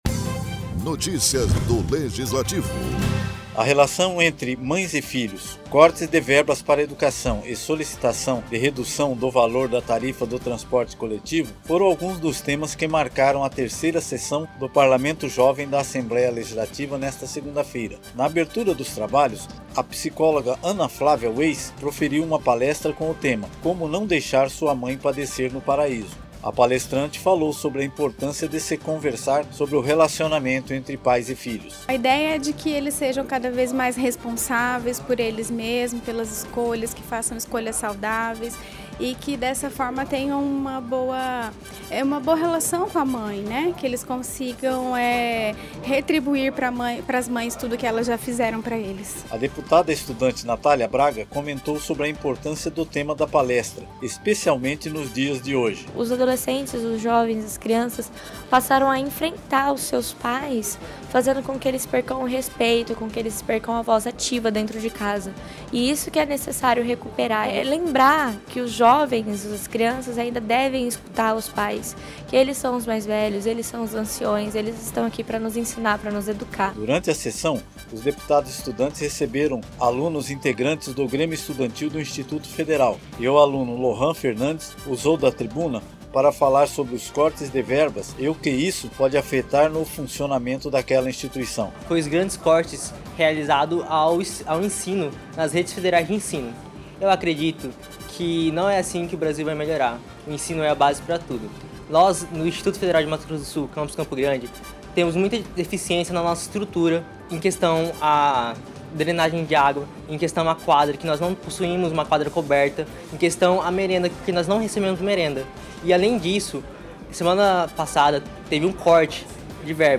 O relação entre mães e filhos, cortes das verbas para educação e solicitação de redução do valor da tarifa do transporte coletivo, foram alguns dos temas que marcaram a terceira sessão do Parlamento Jovem da Assembleia Legislativa nesta segunda-feira.